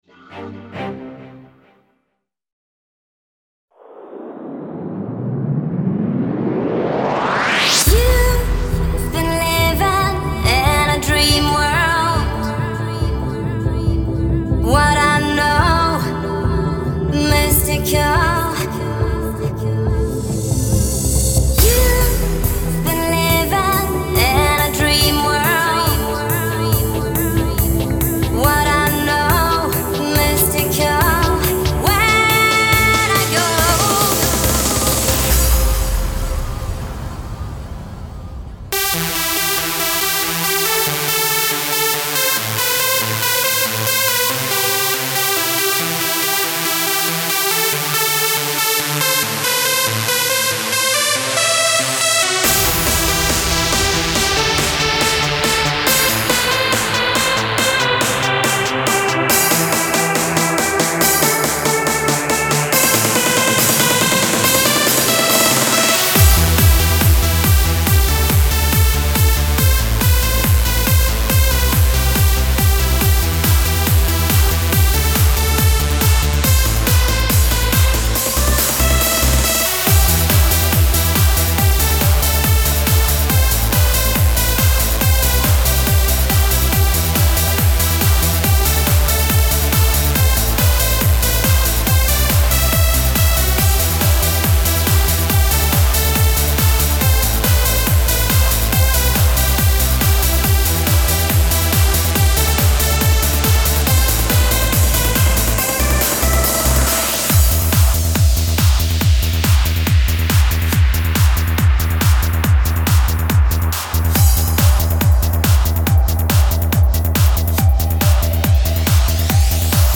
Жанр: Electronica-Trance